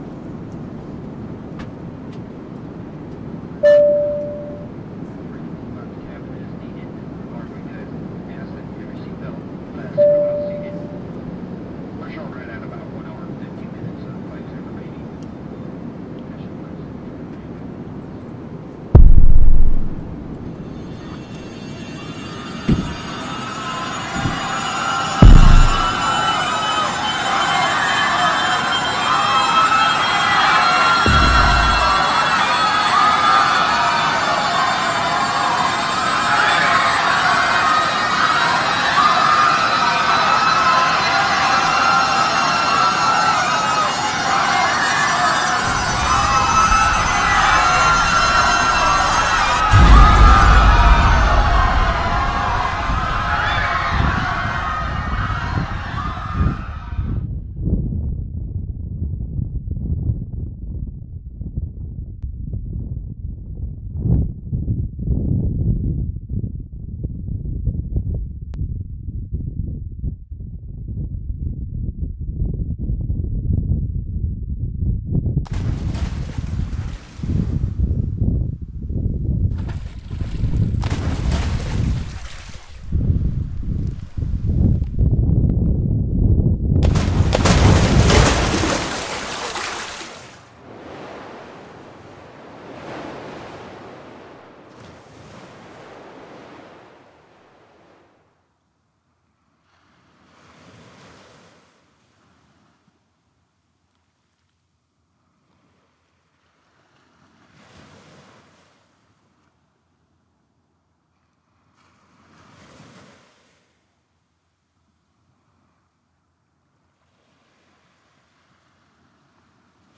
Passenger Plane Crash
5G10B Crash Explosion Freefall mashup mix Plane sound effect free sound royalty free Memes